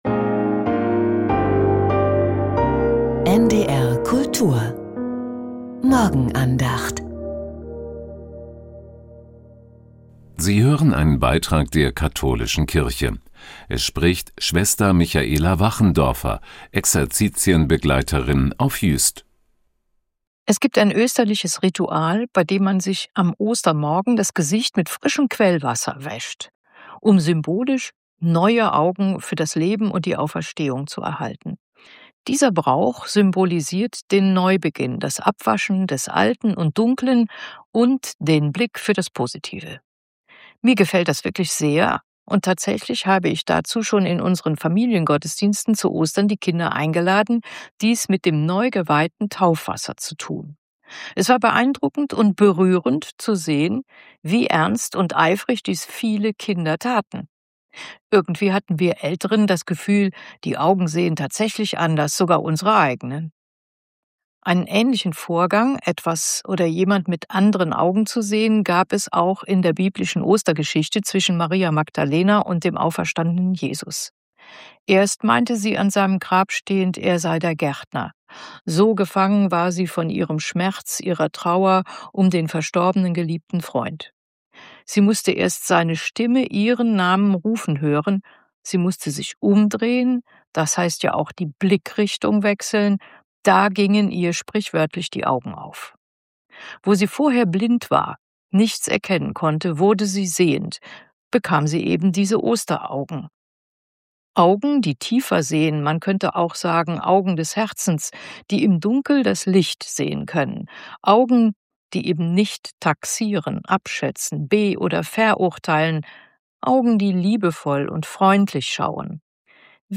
Religion & Spiritualität